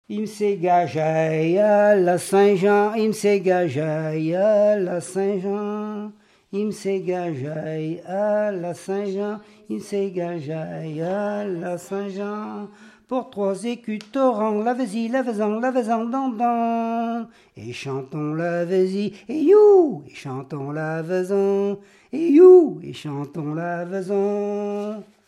Genre laisse
Pièce musicale inédite